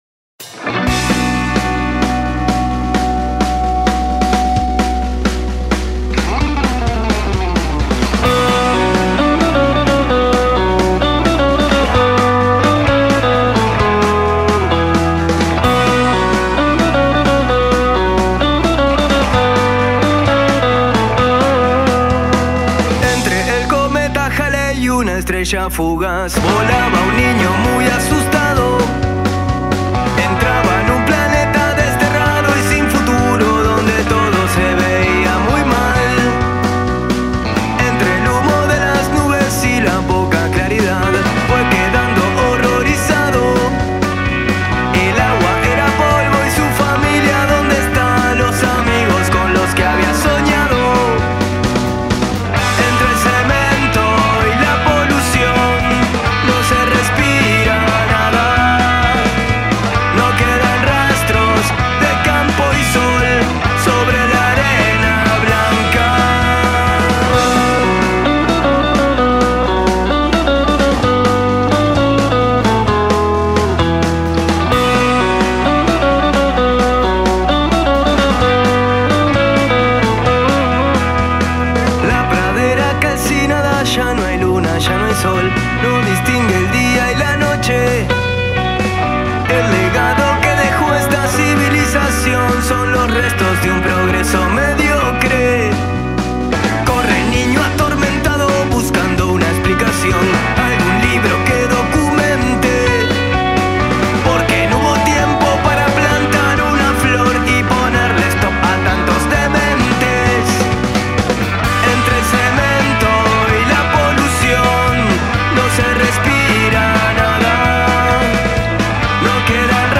Carpeta: Rock uruguayo mp3